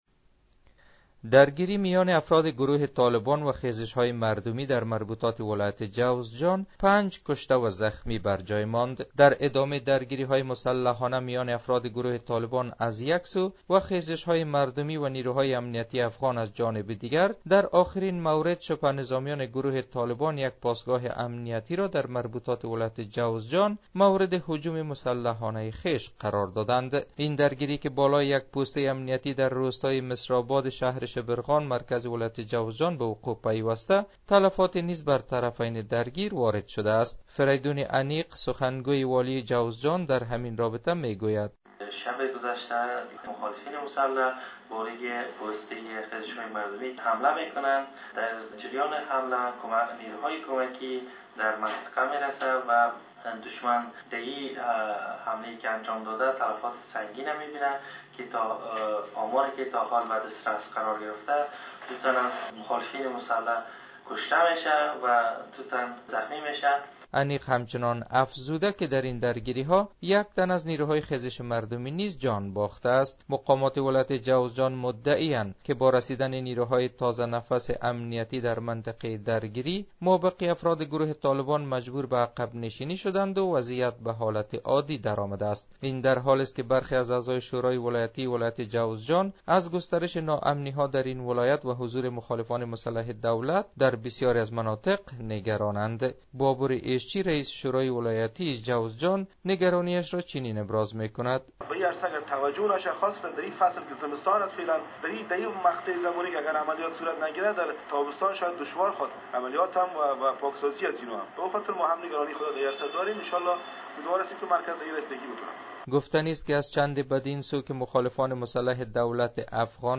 درگیری طالبان در ولایت جوزجان پنج کشته وزخمی برجای گذاشت . جزئیات بیشتر در گزارش خبرنگار رادیو دری از مزارشریف ...